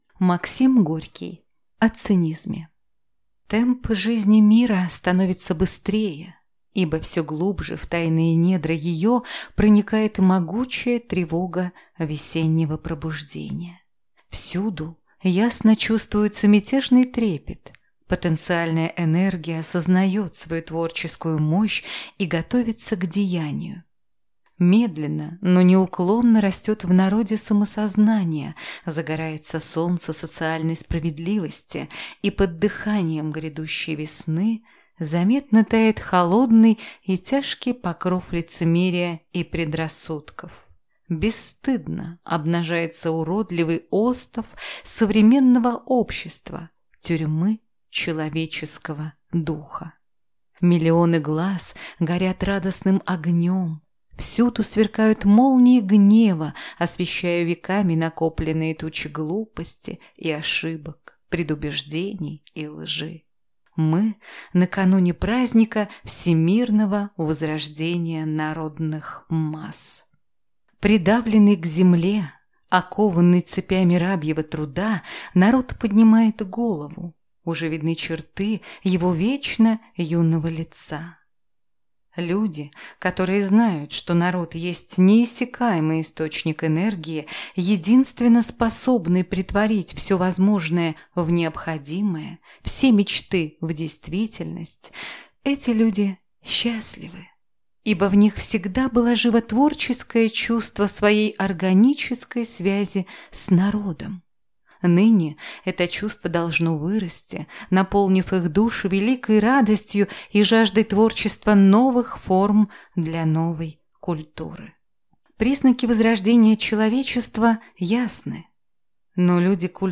Аудиокнига О цинизме | Библиотека аудиокниг